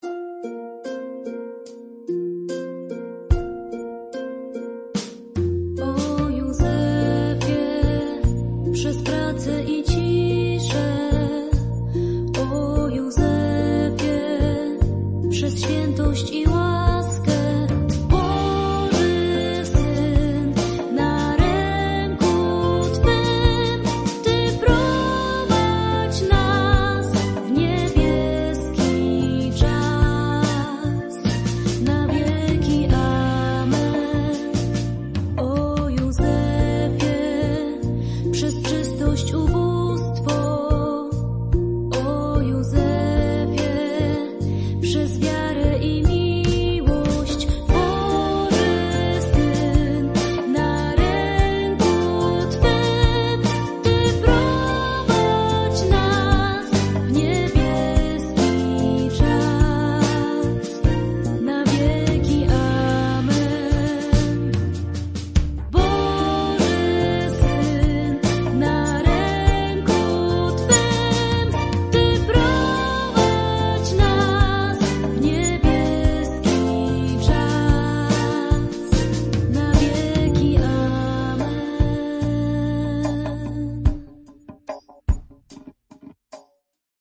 Śpiew ku czci św. Józefa